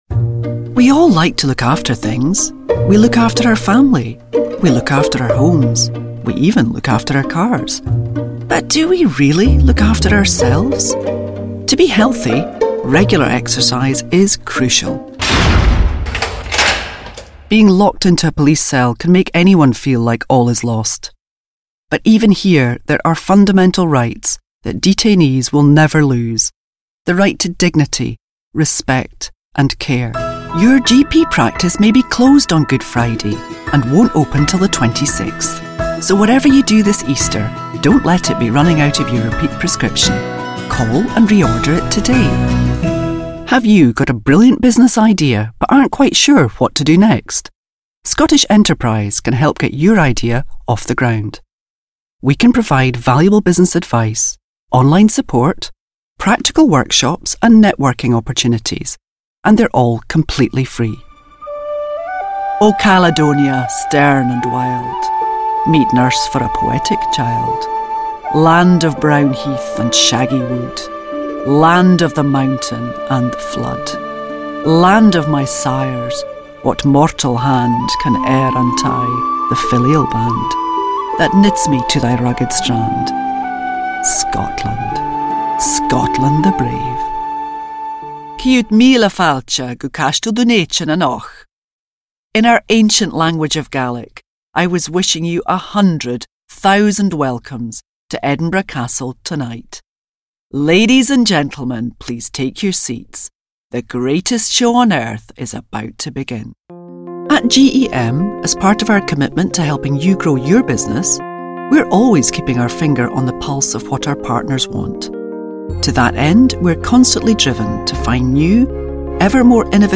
Scottish voice, warm, commanding, authoritative, versatile actress.
schottisch
Sprechprobe: Werbung (Muttersprache):
A trustworthy, warm delivery is assured.